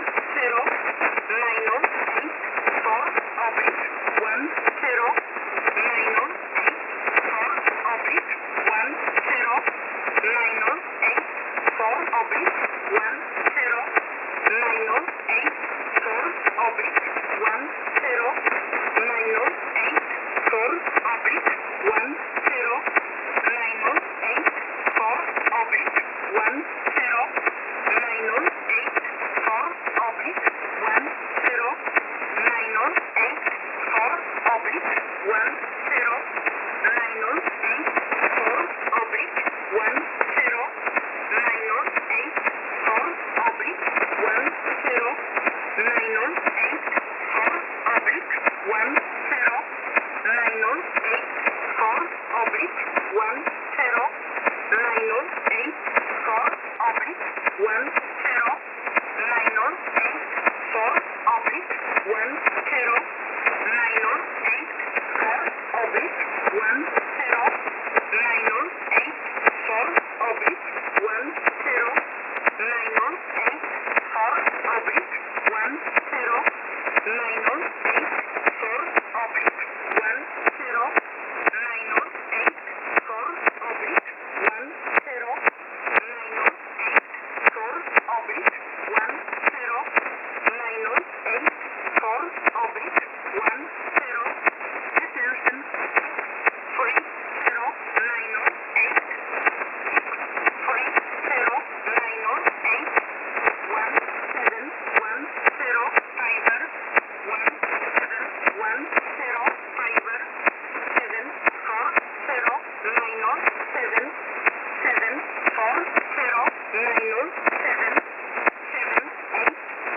Recording: websdr_recording_start_2015-10-10T18_11_55Z_13455.0kHz.wav Date (mm/dd/yy): 101015